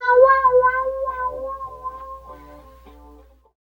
70 GTR 2  -R.wav